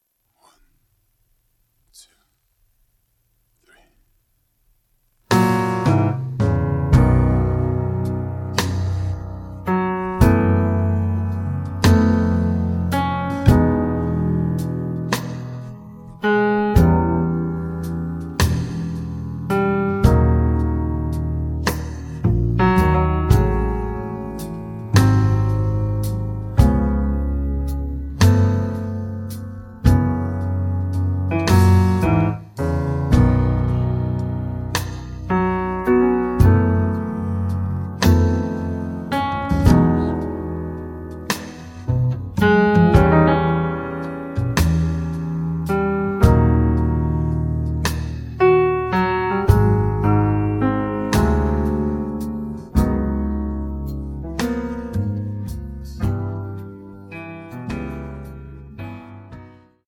음정 -1키 4:07
장르 가요 구분 Voice Cut